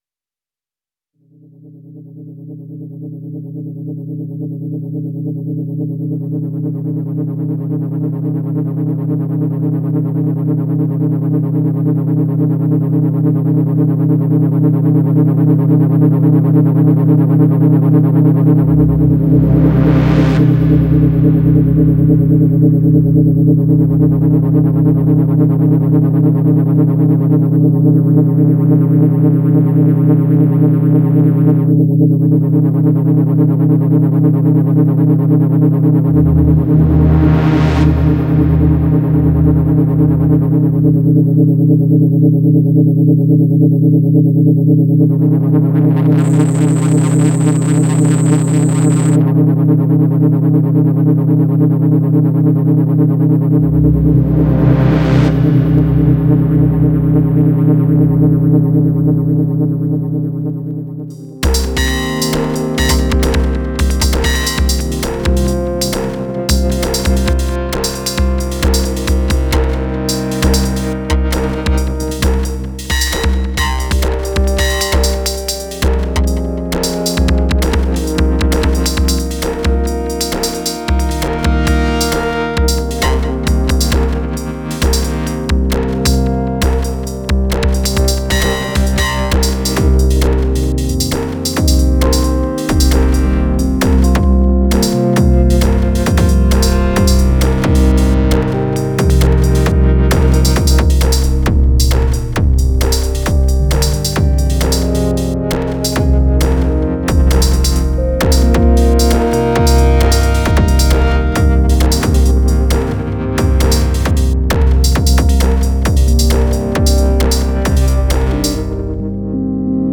GENERATIVE SYNTHESIS IN CONSTANT EVOLUTION — ART AND TECHNIQUE IN SYMBIOSIS
Drones-Depths-Demo-MP3.mp3